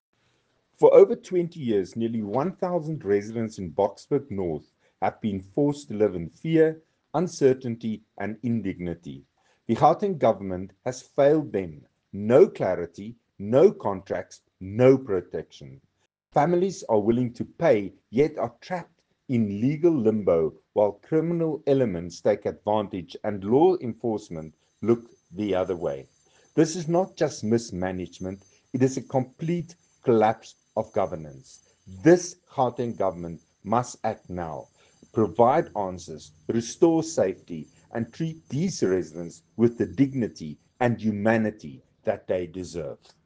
soundbite by Nico De Jager MPL.